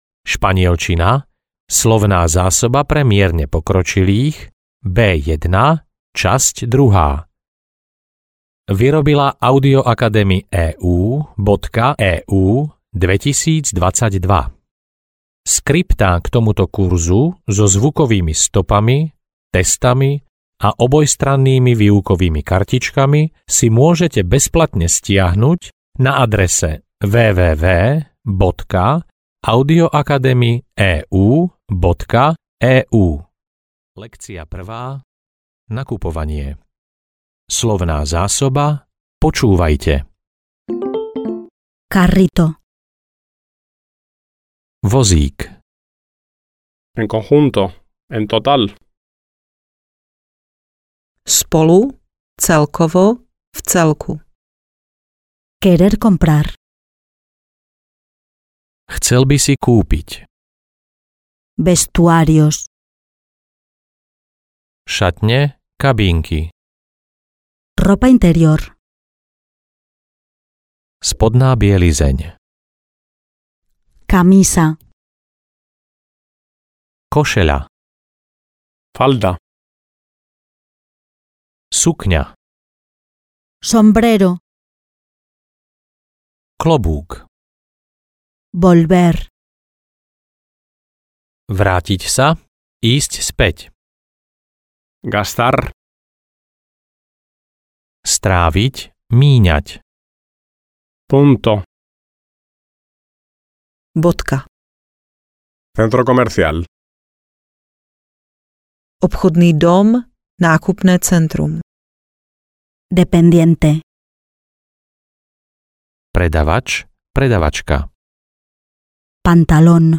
Španielčina pre mierne pokročilých B1 – časť 2 audiokniha
Ukázka z knihy